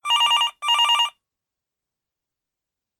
ring-BVLmlpZb.ogg